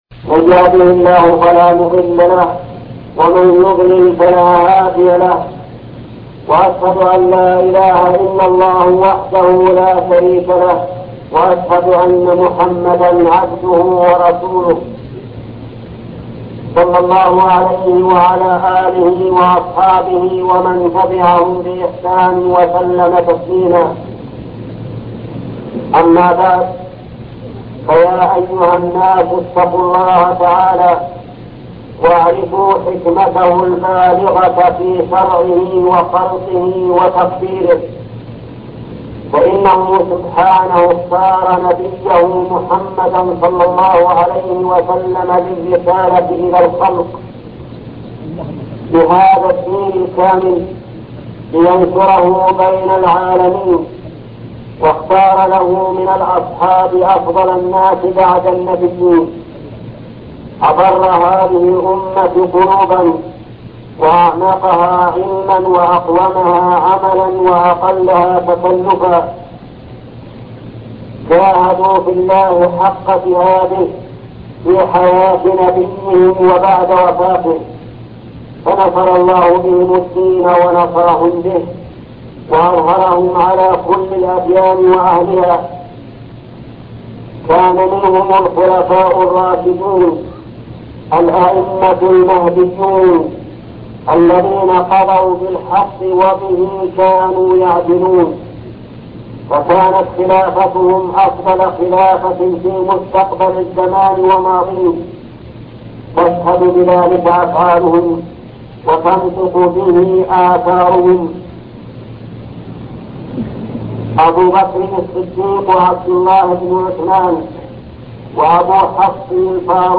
خطبة سيرة عمر بن الخطاب الشيخ محمد بن صالح العثيمين